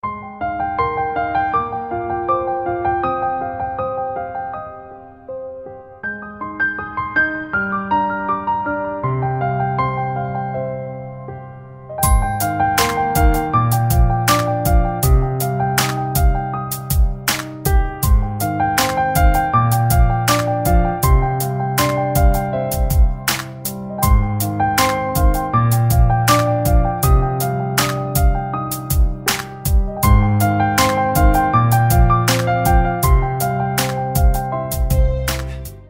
• Категория: Красивые мелодии и рингтоны